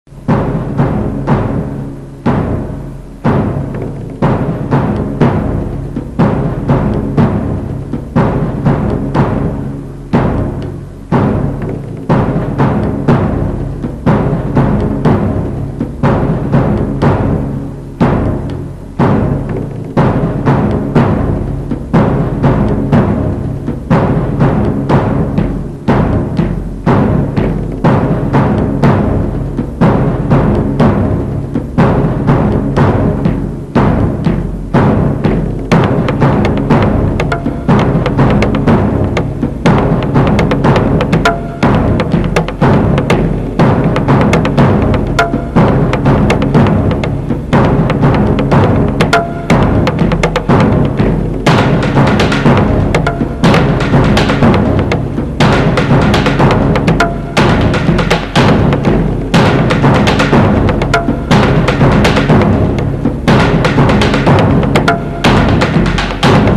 8. Боевые барабаны перед атакой